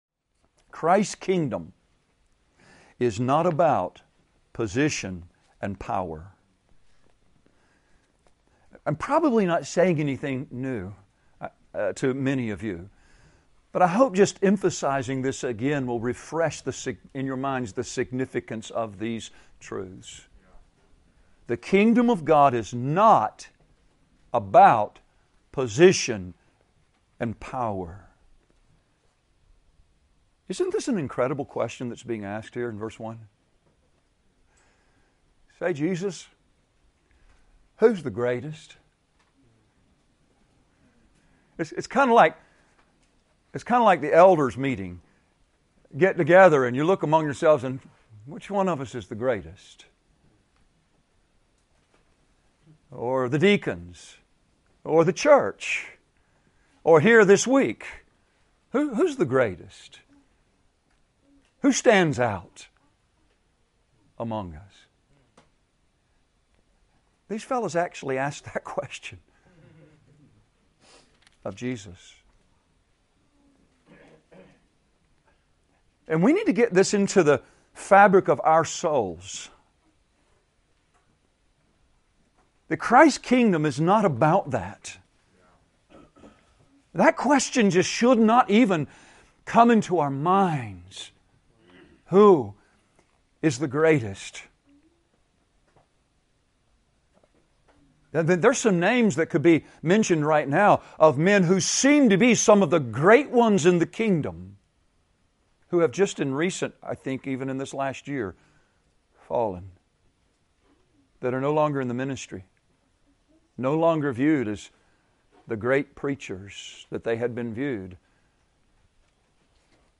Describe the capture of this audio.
Excerpt | 5:40 | From Part 3 of 2018 Men's Retreat | The kingdom of God is not about position and power.